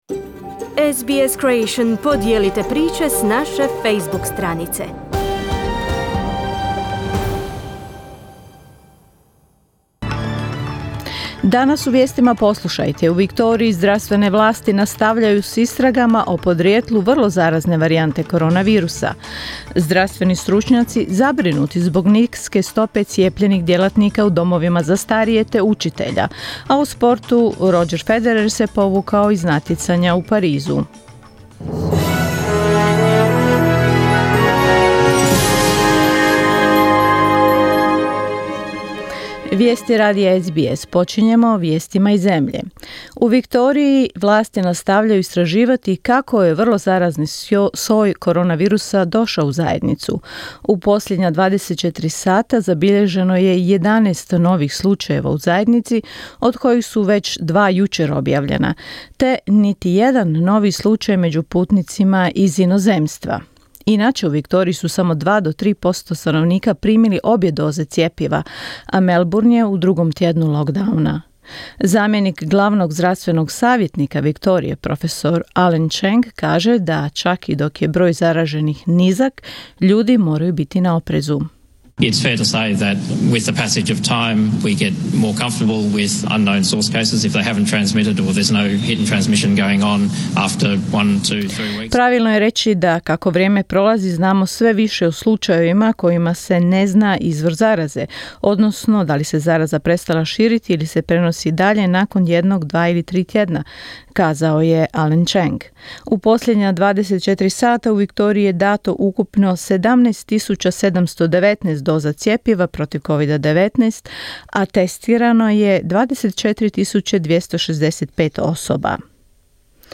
Vijesti radija SBS na hrvatskom jeziku